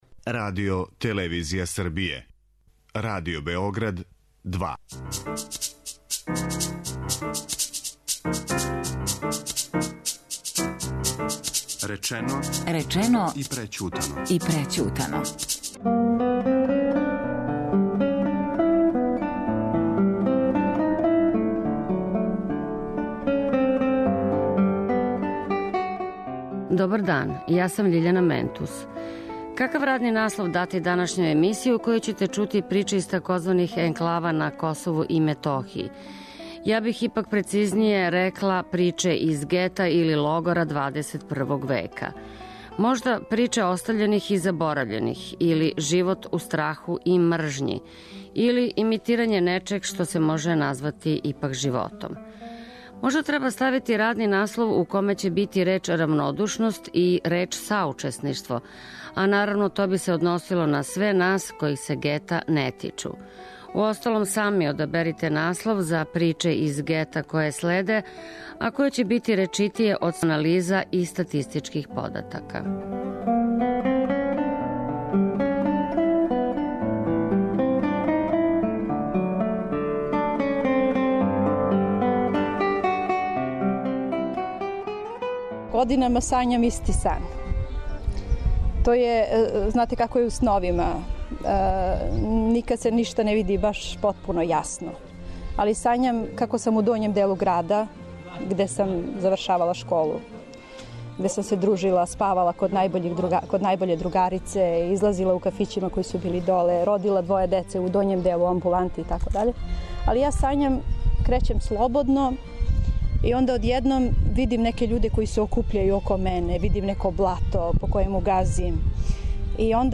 У данашњој емисији ћемо вам пренети звучне слике из Ораховца на Косову и Метохији.